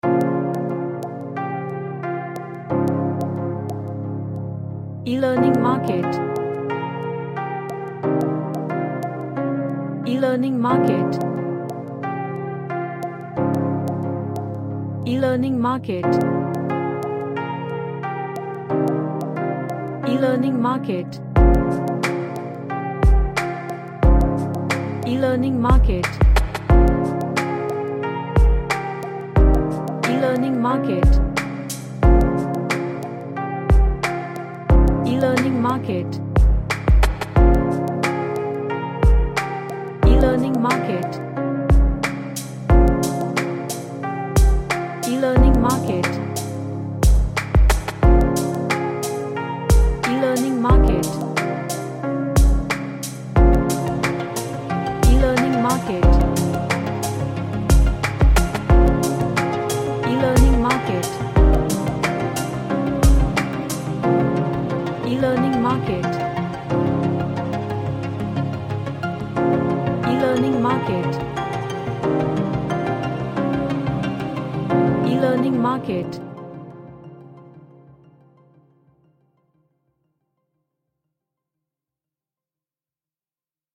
A sad lofi track with elements creating mystery.
Sad / NostalgicDark